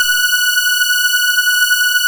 Index of /90_sSampleCDs/InVision Interactive - Keith Emerson Lucky Man/Partition G/SYNTH LAYER2